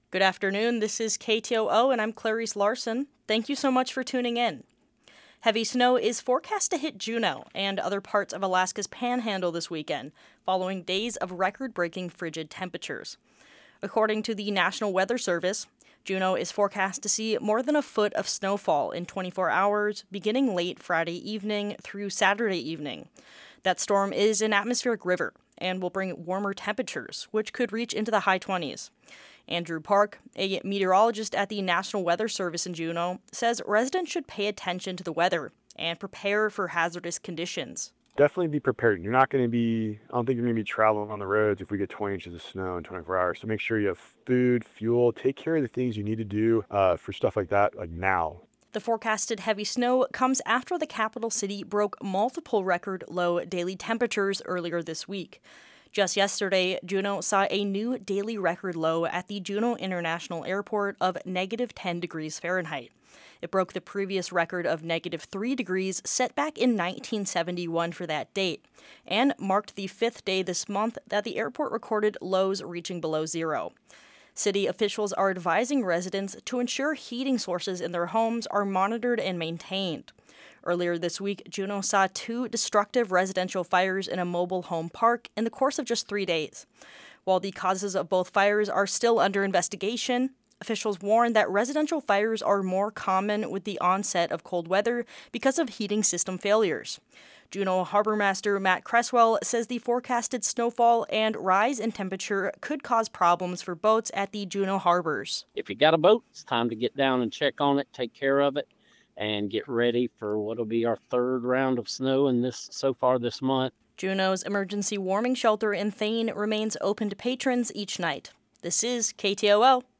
Newscast – Wednesday, Dec. 24, 2025